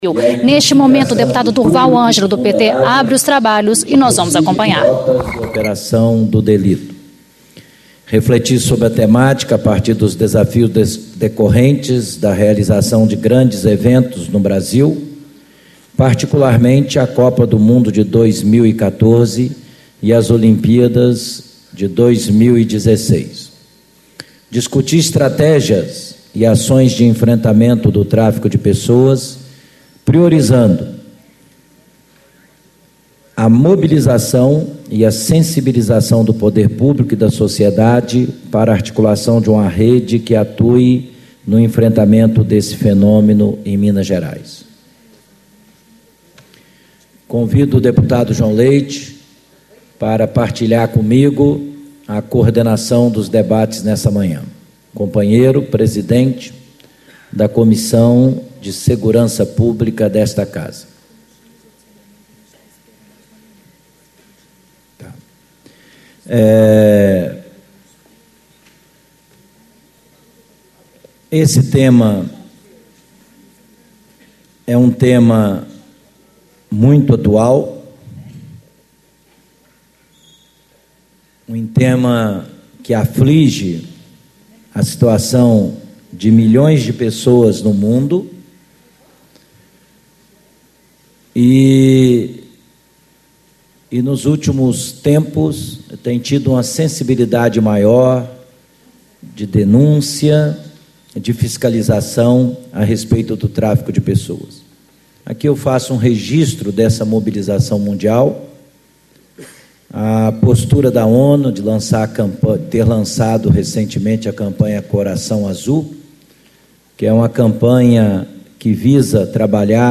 Abertura - Deputado Durval Ângelo, PT - Presidente da Comissão de Direitos Humanos
Discursos e Palestras